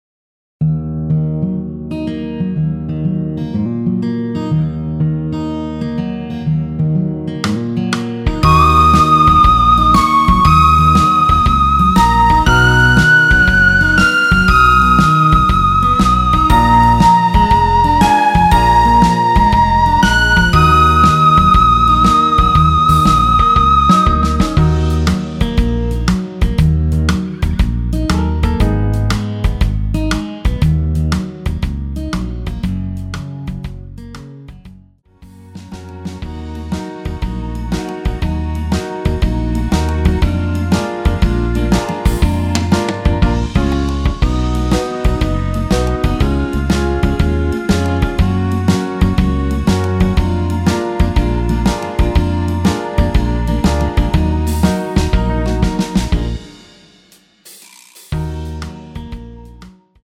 엔딩이 페이드 아웃으로 끝나서 라이브에 사용하실수 있게 엔딩을 만들어 놓았습니다.
원키에서(-1)내린 MR입니다.
Ebm
앞부분30초, 뒷부분30초씩 편집해서 올려 드리고 있습니다.